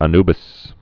(ə-nbĭs, ə-ny-)